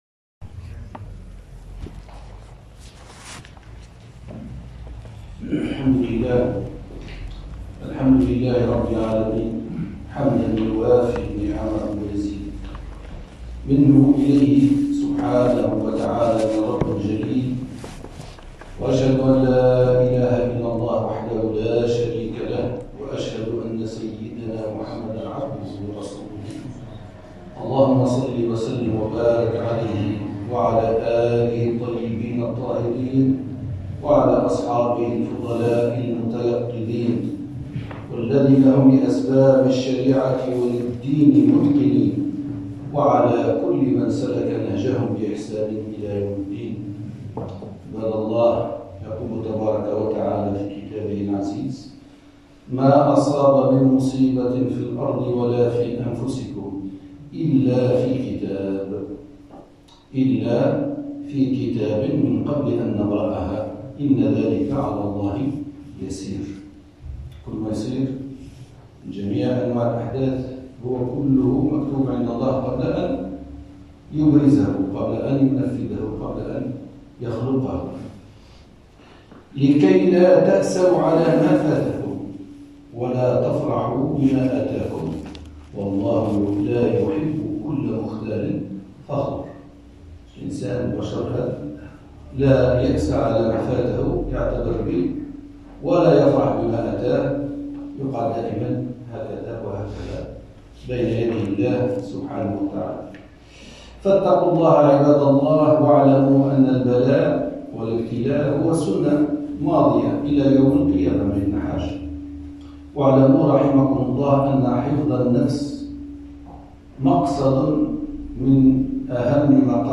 خطبة الجمعة: الرجوع بعد الكورونا